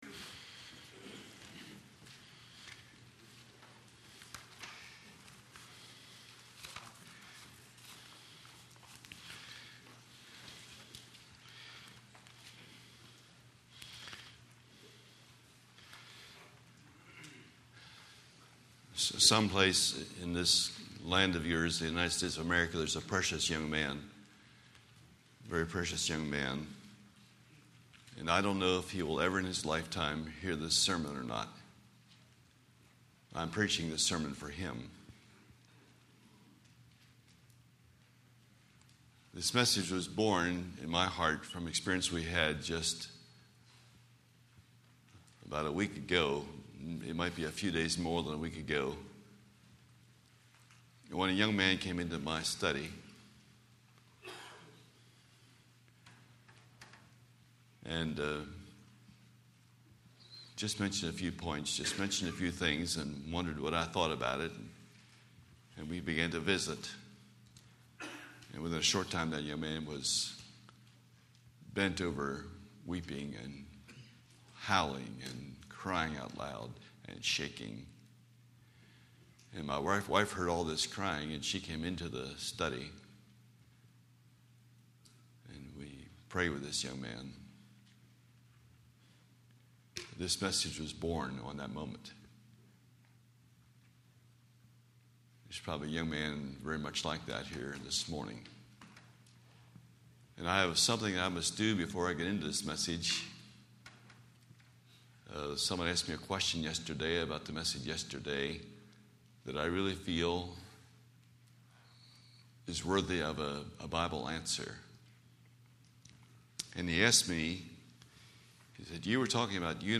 Book of Ephesians Service Type: Midweek Meeting Speaker